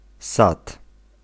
Ääntäminen
Synonyymit ogród Ääntäminen Tuntematon aksentti: IPA: /s̪at̪/ Haettu sana löytyi näillä lähdekielillä: puola Käännös Ääninäyte Substantiivit 1. orchard US 2. garden UK US Suku: m .